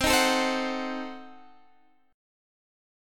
Cdim7 chord